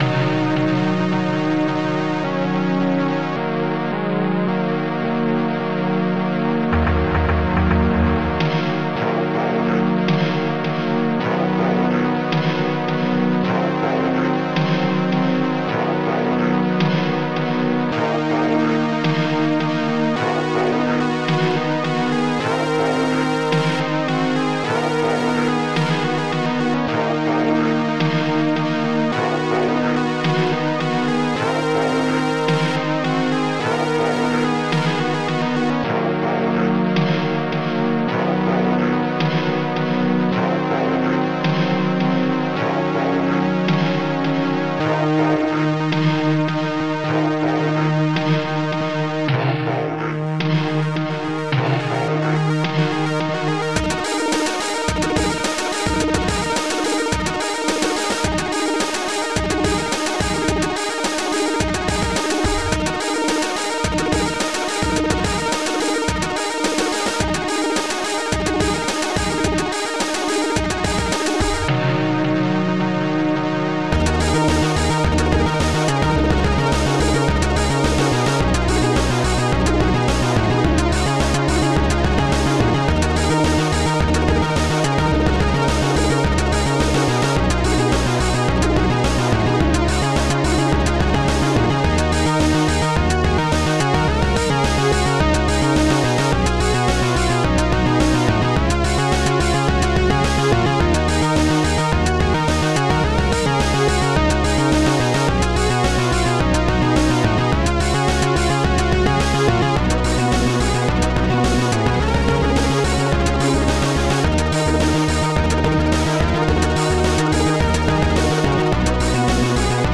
ST-16:-pitchsynth
ST-16:robotvoice2
ST-04:bassdrum10
ST-17:blsnarefed2
ST-17:blhihat-
ST-11:ronkhihat